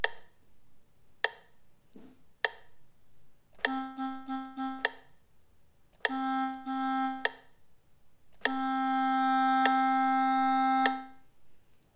A continuación escoitaredes unha serie de códigos morse coma os que acabamos de aprender, e deberemos escoller de entre as diferentes opcións que se presentan a imaxe que máis se axusta a cada código.